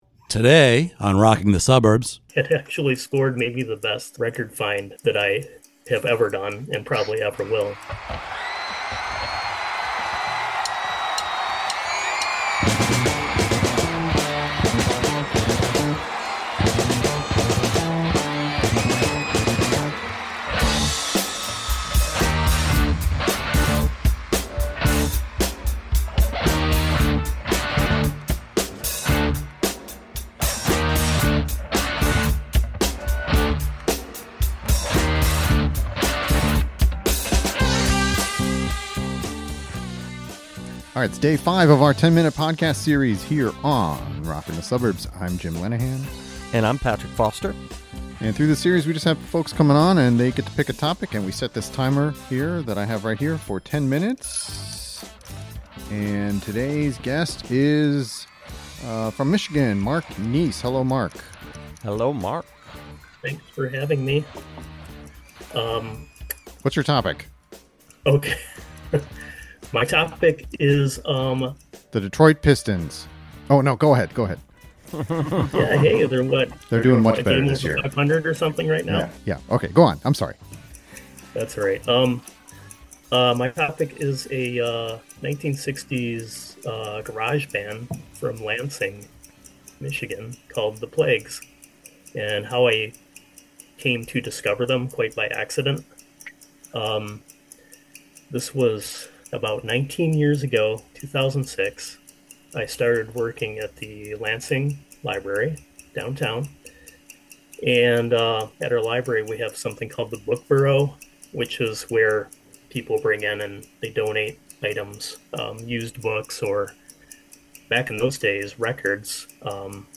We recently held a virtual podcast recording where we invited participants to come up with a topic of their choice.